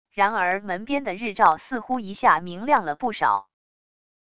然而门边的日照似乎一下明亮了不少wav音频生成系统WAV Audio Player